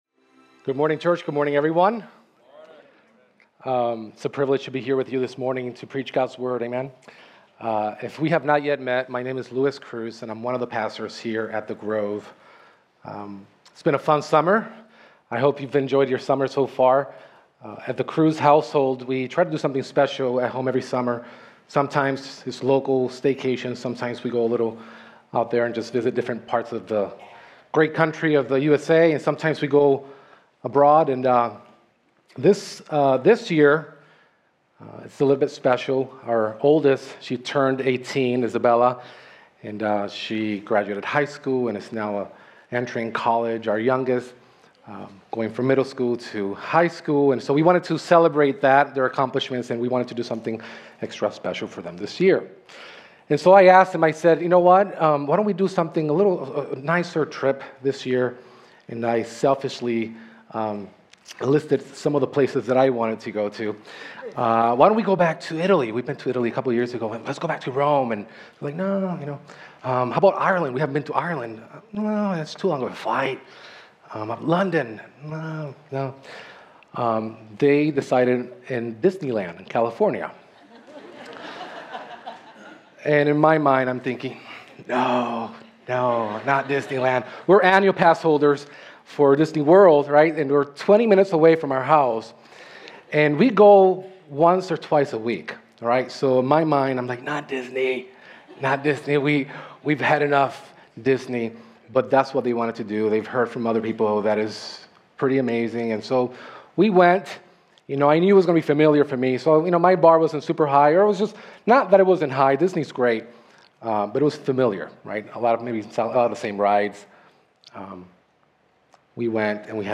Home Sermons Summer Psalms 2025